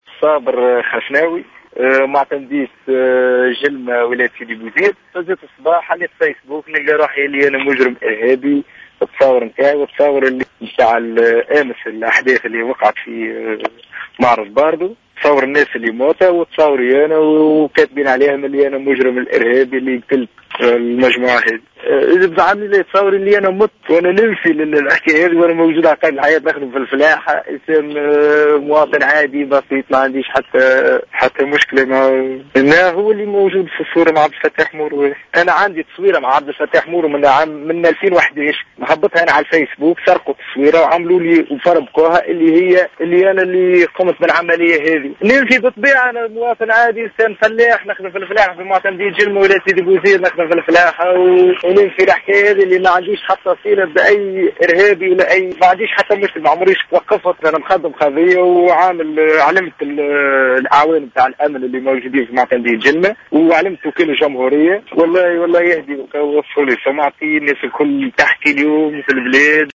وأوضح الشاب في اتصال هاتفي بجوْهرة أف أم اليوم أنّ صورة جمعته بالنائب الأولّ لرئيس مجلس نوّاب الشعب و القيادي في حركة النهضة عبد الفتّاح مورو التقطت سنة 2011 وقام بنشرها على صفحته الخاصة على الفايس بوك و لكن تمّ استعمالها و توظيفها في العملية الإرهابية.